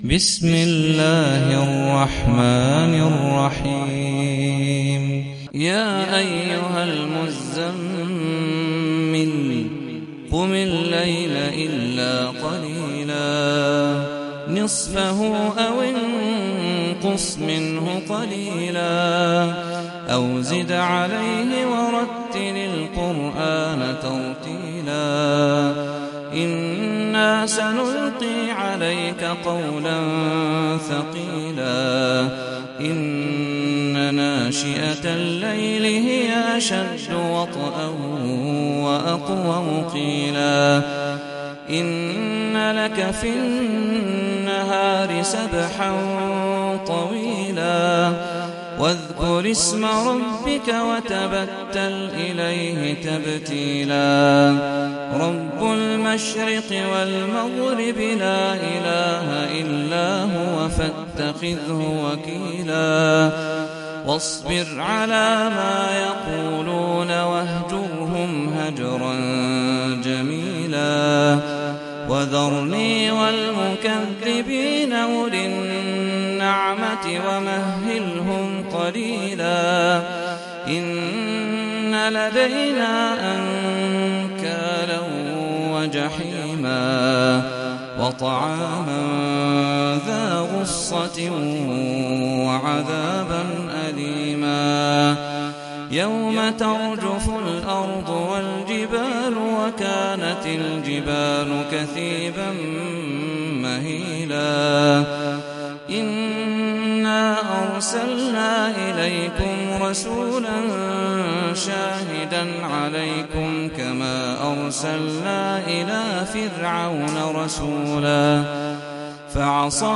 سورة المزمل - صلاة التراويح 1446 هـ (برواية حفص عن عاصم)